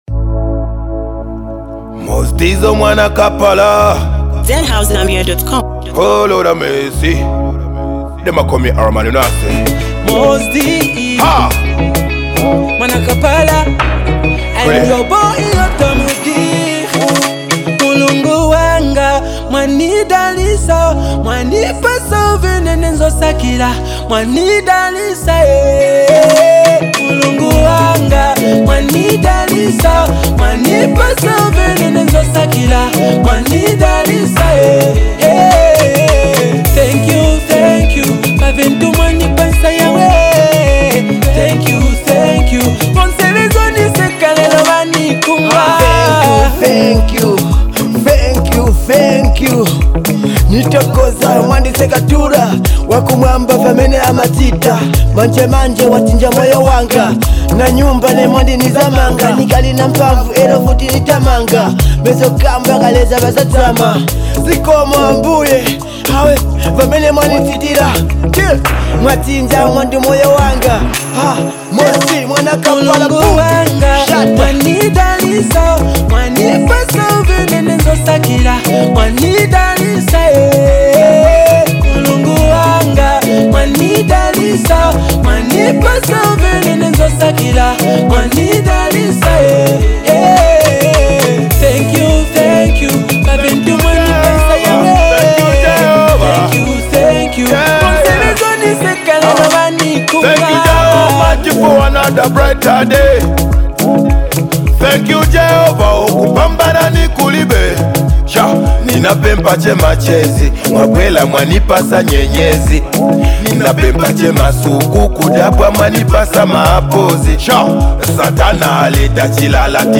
a soulful track